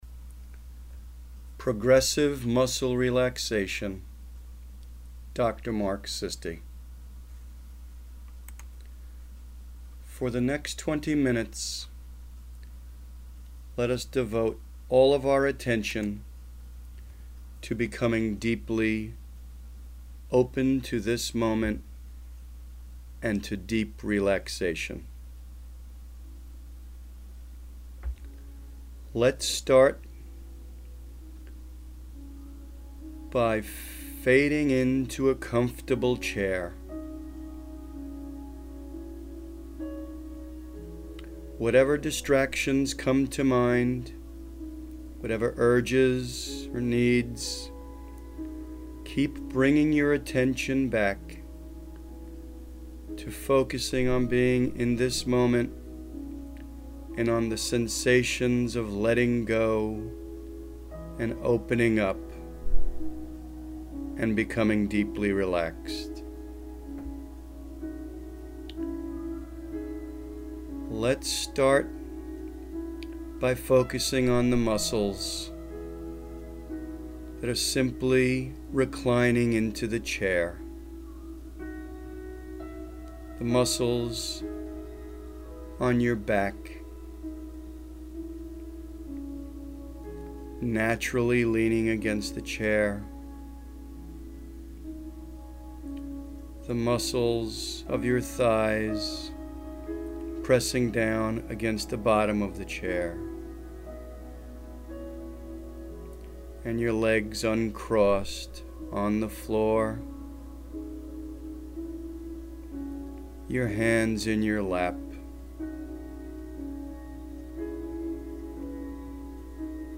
Progressive Muscle Relaxation (AUDIO MP3)